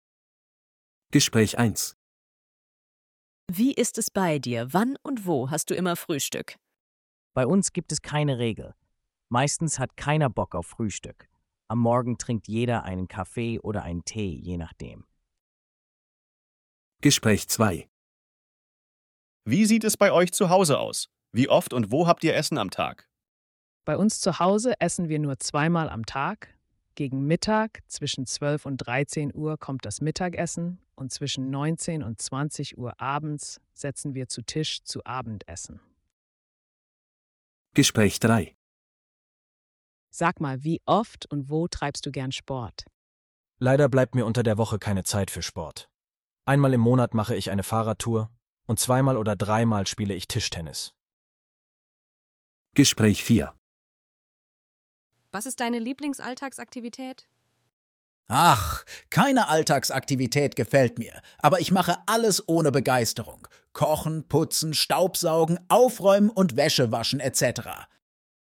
Hörtext für die Dialoge bei Aufgabe 4: